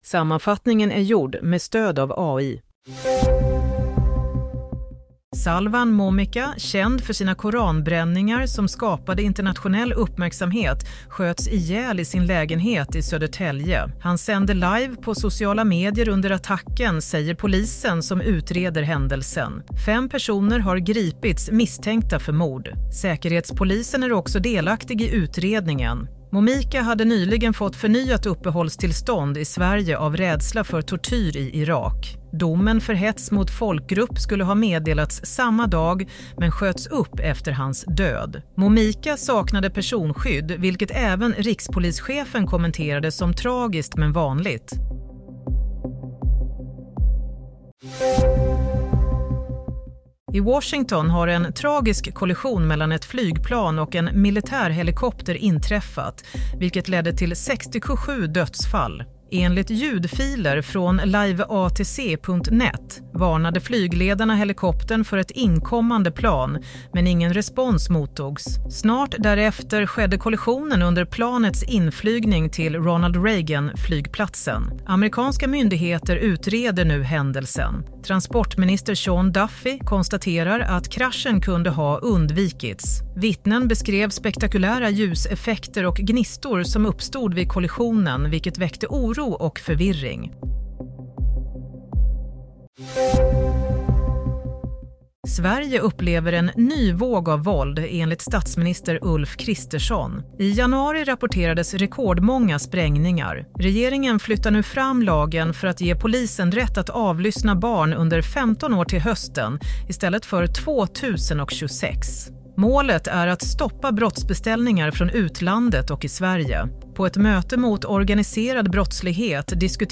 Play - Nyhetssammanfattning – 30 januari 16.00